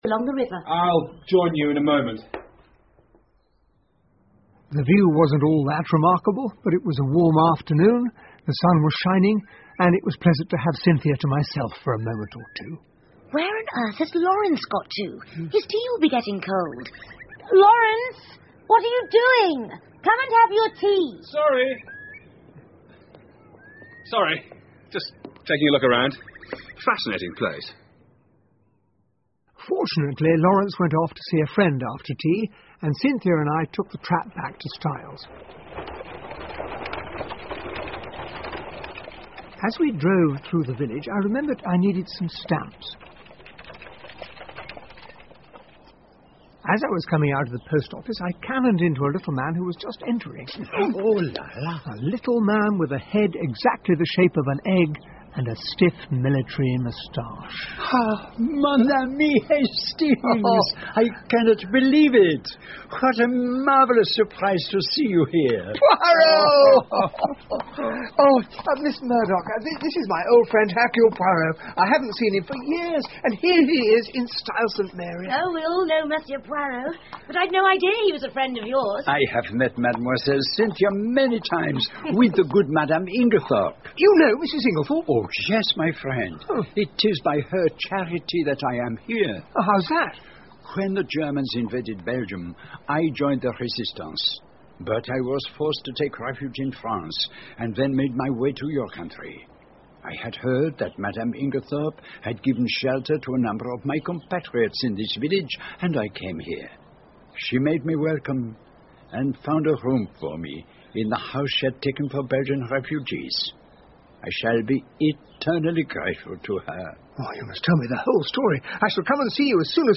英文广播剧在线听 Agatha Christie - Mysterious Affair at Styles 5 听力文件下载—在线英语听力室